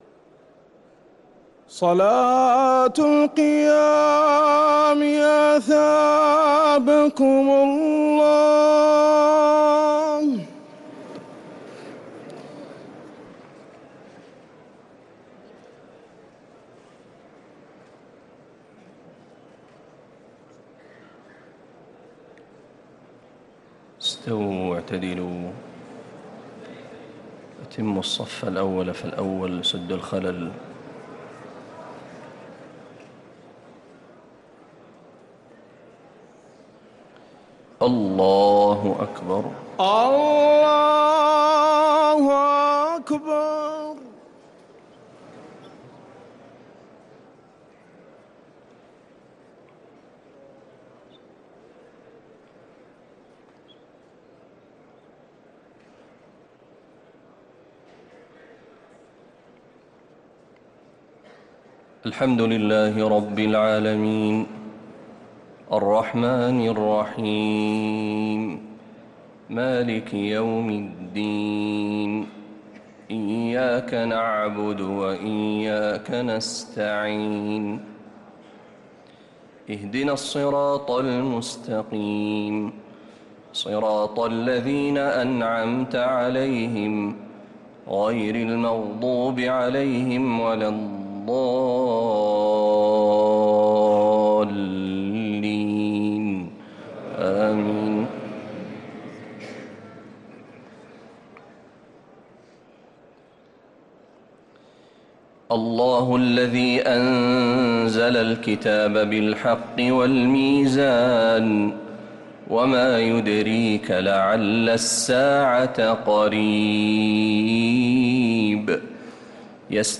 Ramadan Tarawih